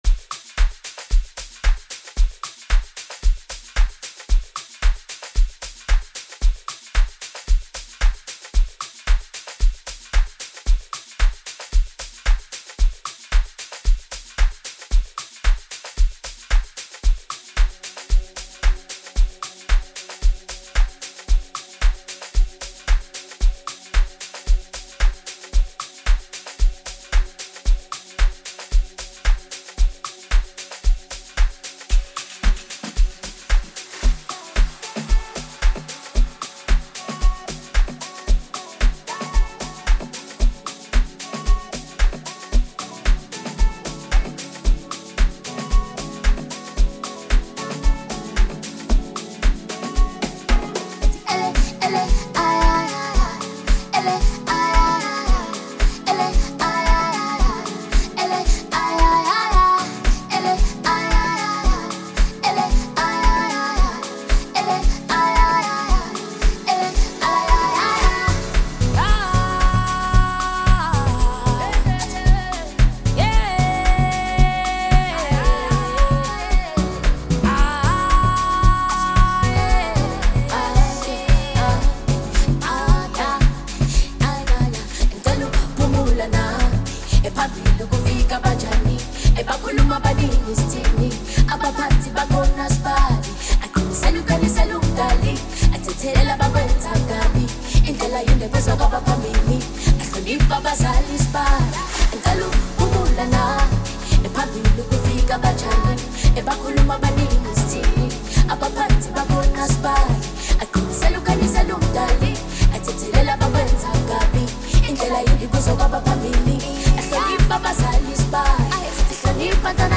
Amapiano song
guitar